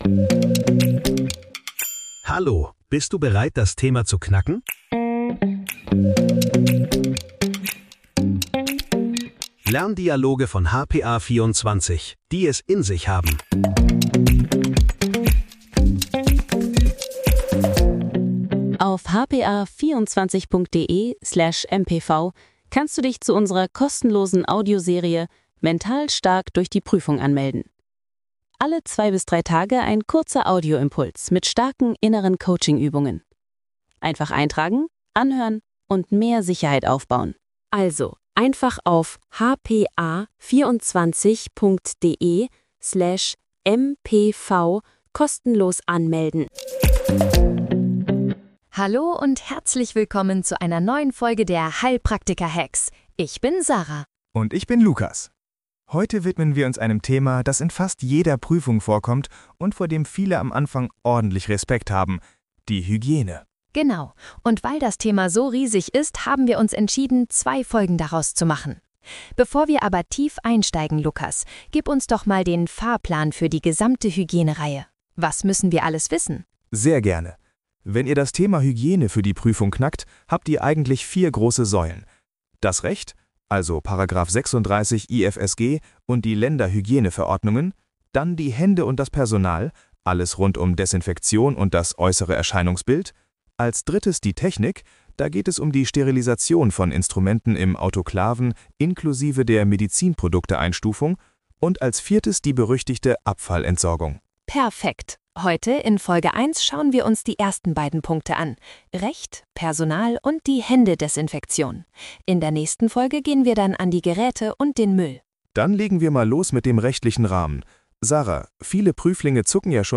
Lerndialoge für deinen Prüfungserfolg